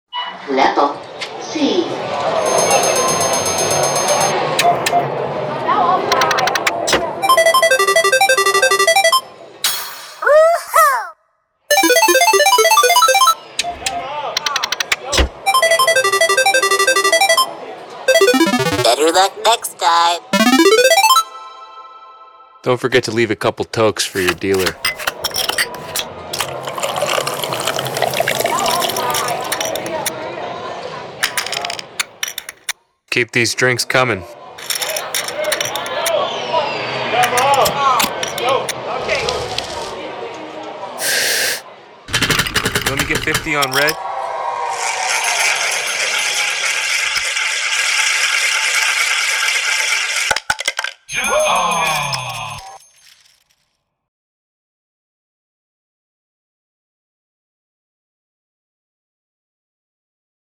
Foley
The bustling lively hubbub of a casino is like no place on earth.